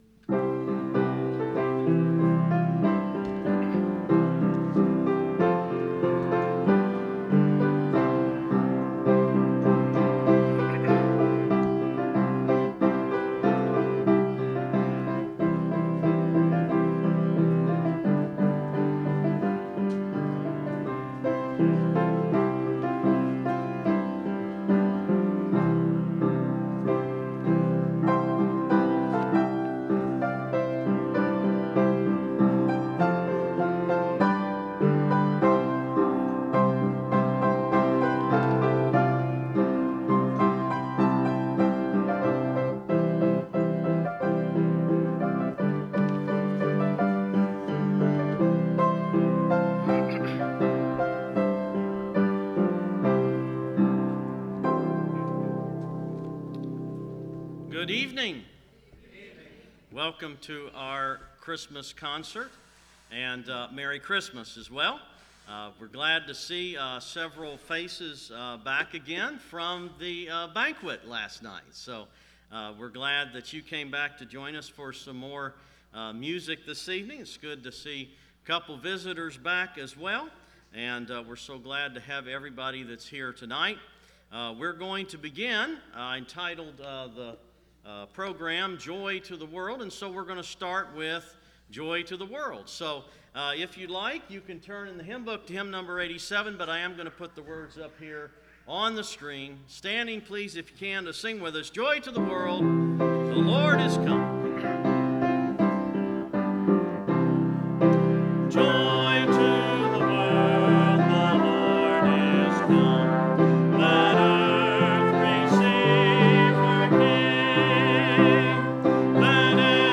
2021 Christmas Service